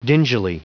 Prononciation du mot dingily en anglais (fichier audio)
Prononciation du mot : dingily